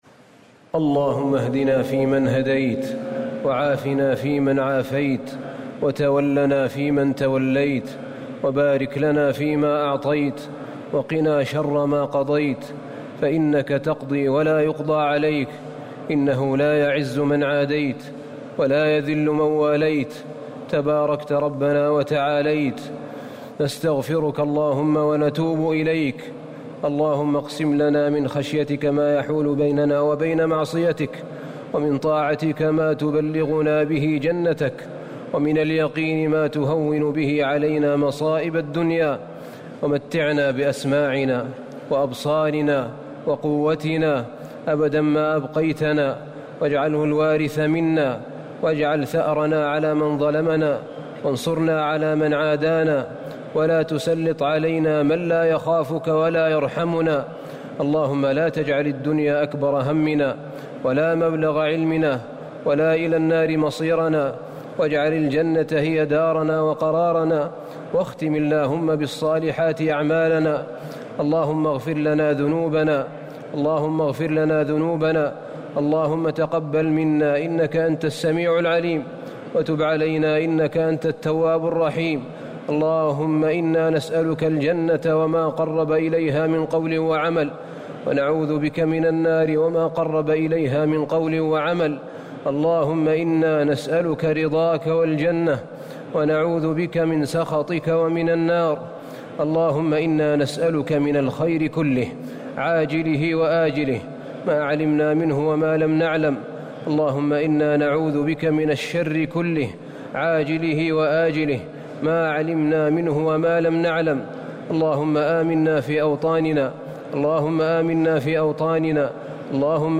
دعاء القنوت ليلة 3 رمضان 1439هـ | Dua for the night of 3 Ramadan 1439H > تراويح الحرم النبوي عام 1439 🕌 > التراويح - تلاوات الحرمين